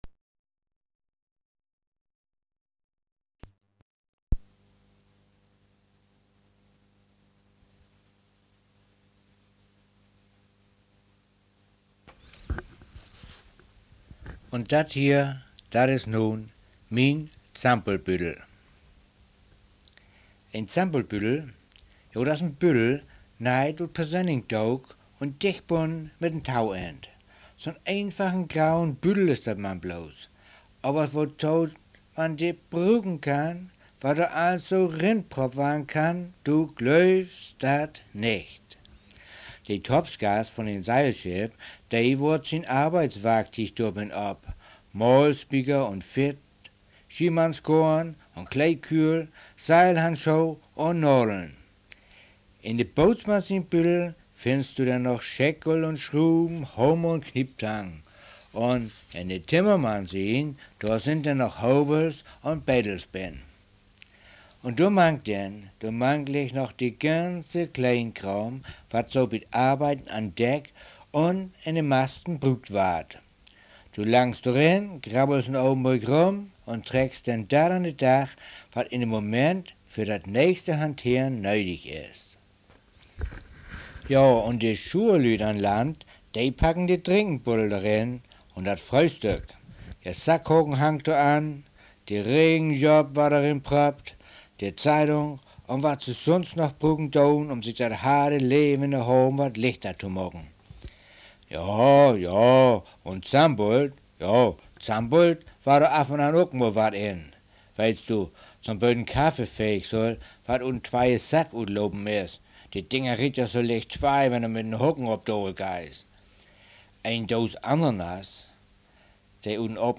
In der Papierfassung liegt eine (Mini-)CD mit Auszügen des Textes bei, gelesen vom Autor